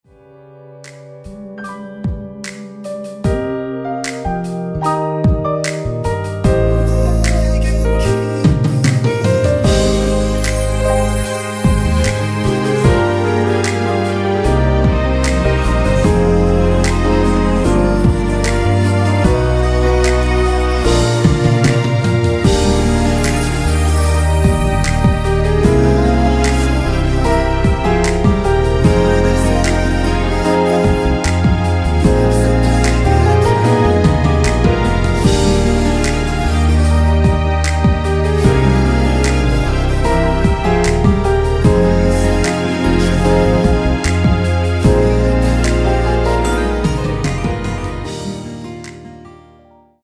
원키에서 (-1)내린 코러스 포함된 MR 입니다.
Eb
음질이 매우 좋네요
음질이나 코러스버전은 좋네요
앞부분30초, 뒷부분30초씩 편집해서 올려 드리고 있습니다.
축가 MR